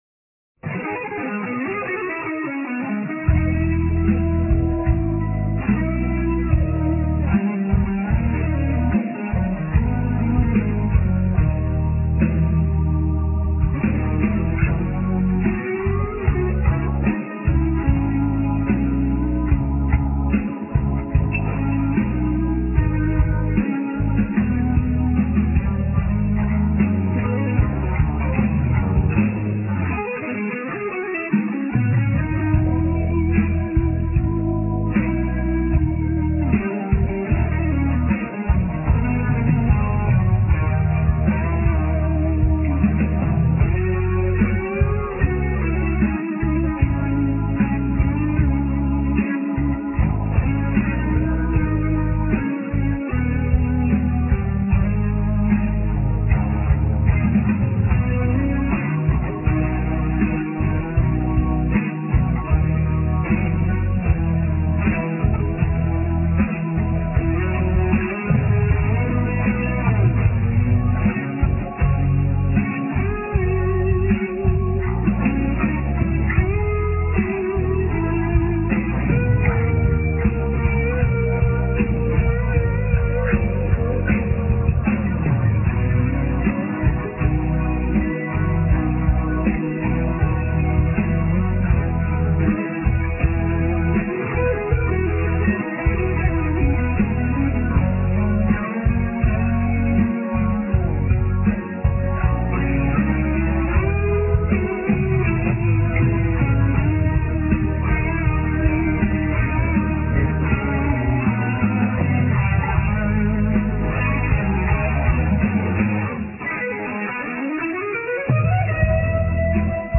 Vocals
Guitars
Bass
Drums
Keyboards